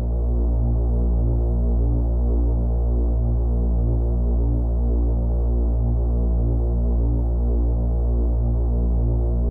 rechargebattery.ogg